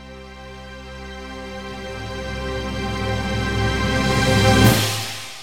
Category: Cinematic Ringtones